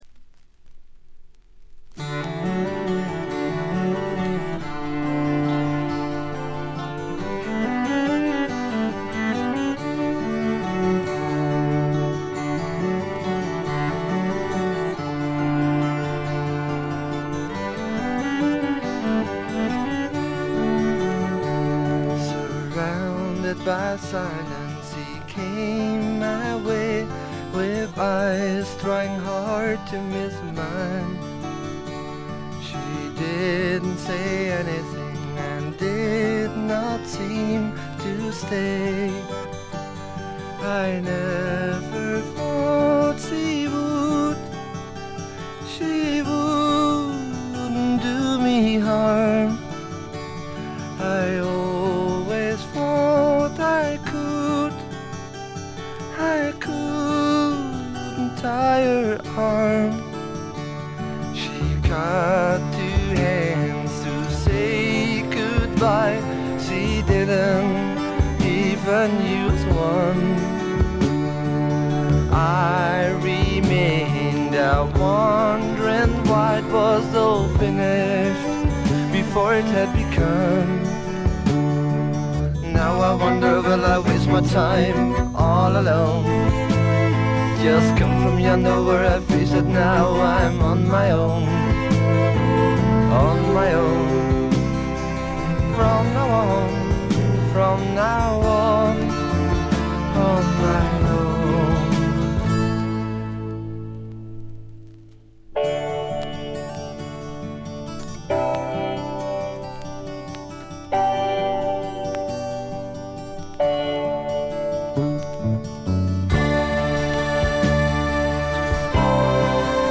ざっと全編試聴しました。バックグラウンドノイズ、チリプチやや多め大きめ。
全体はチェンバー・ロック風な雰囲気ですが、フォークロックとか哀愁の英国ポップ風味が濃厚ですね。
試聴曲は現品からの取り込み音源です。